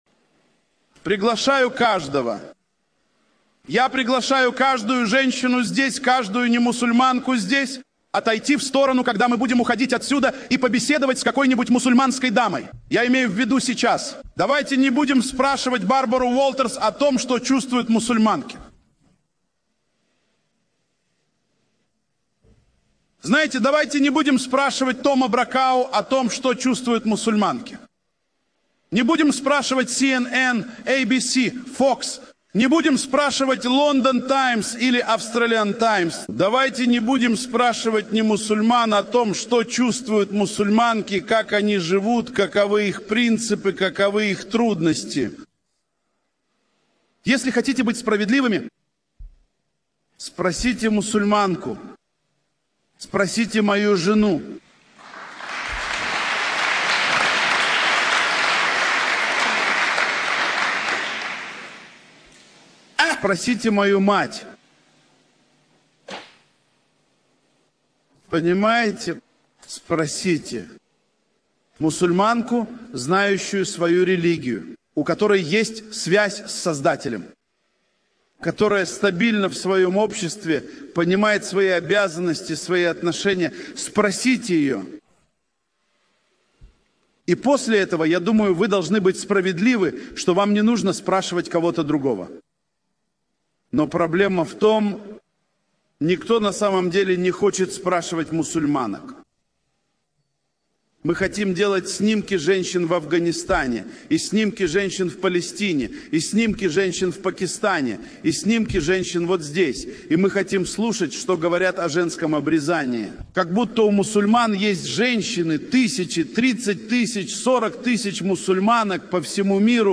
Это видео — прекрасный фрагмент из одной из лекций